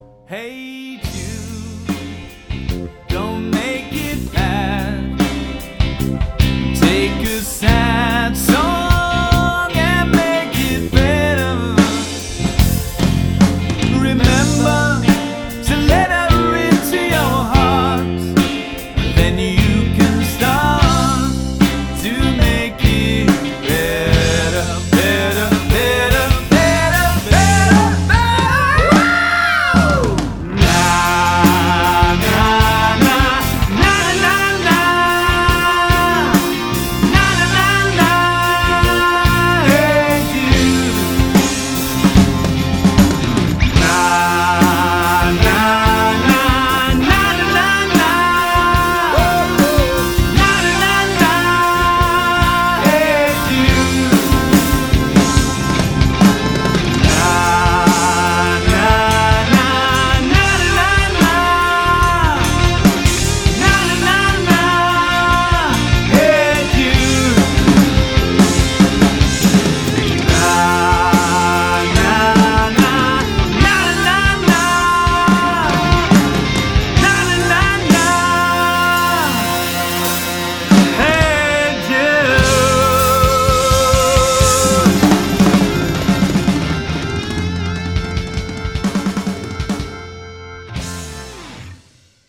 • Versatile 3- to 9-piece party band